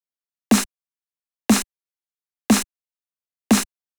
34 Snare.wav